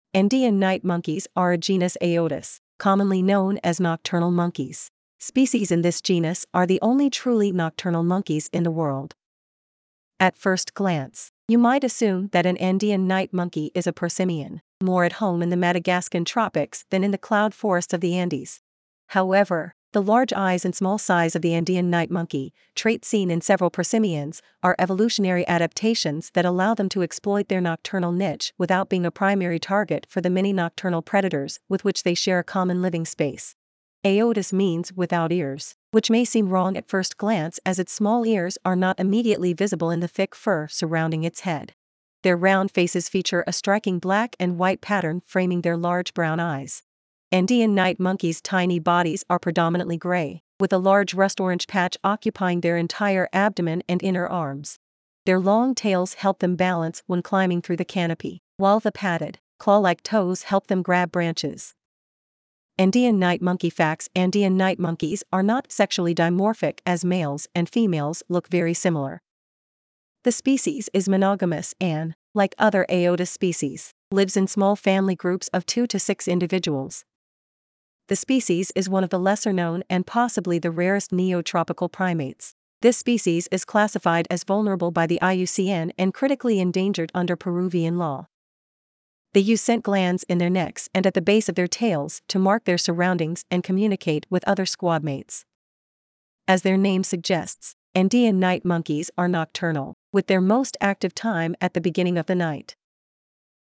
Andean Night Monkey (Aotus miconax)
Andean-Night-Monkey.mp3